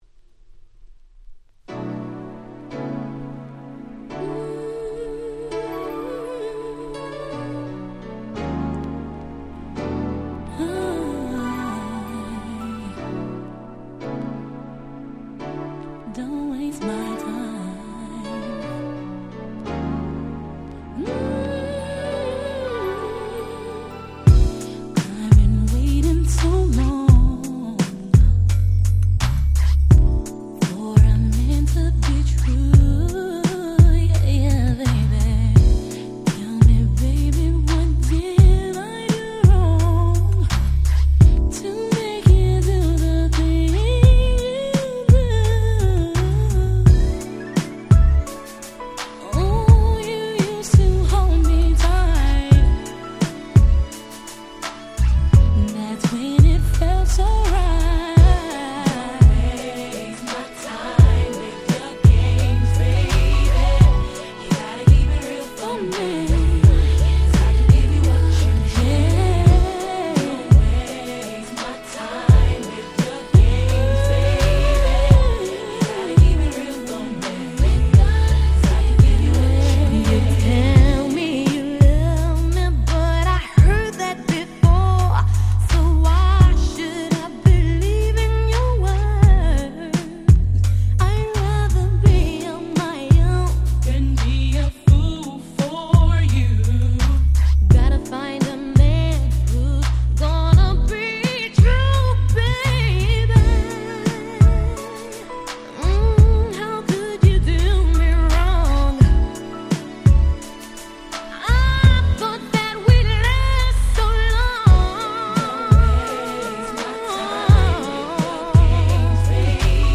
96' Very Nice Slow Jam / R&B / Hip Hop Soul !!